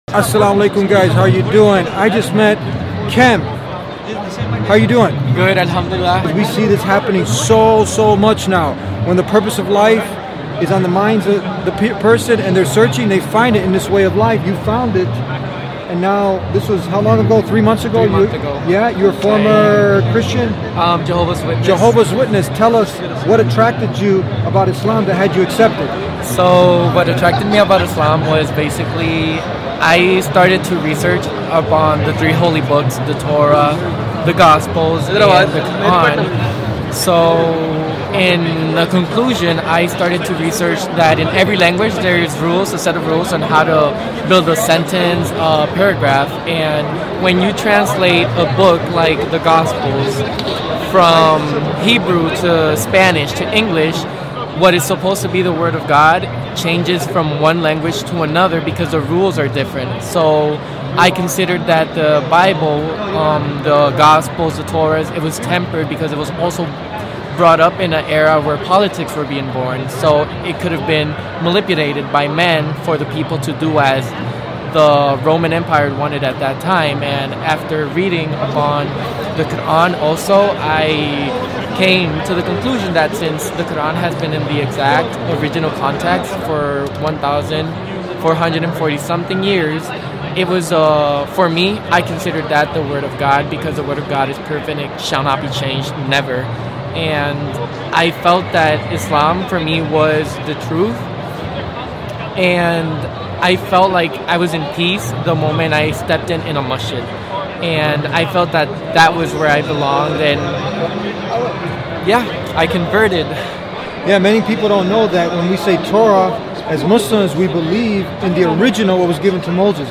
He has been producing educational content about Islam for over 18 years, interviewing scholars, converts, and experts on faith, purpose, and contemporary issues.